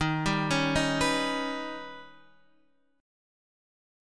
Eb+7 chord